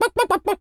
chicken_cluck_bwak_seq_11.wav